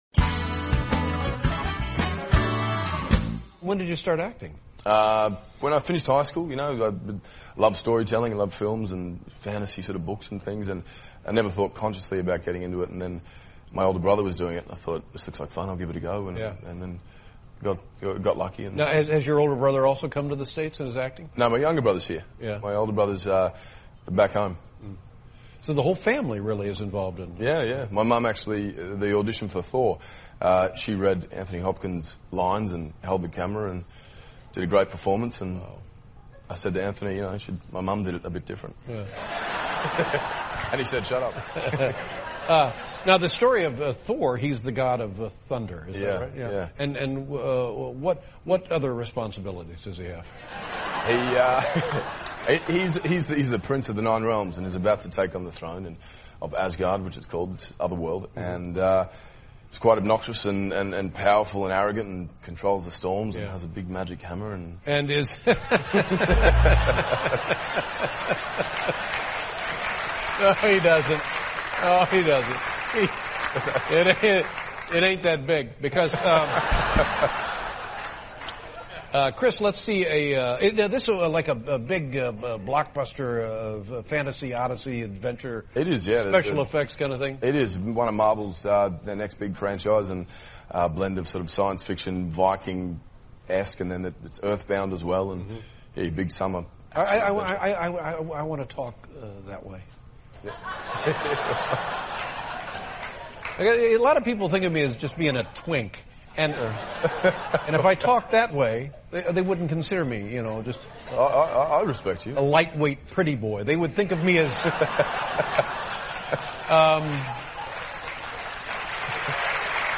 访谈录: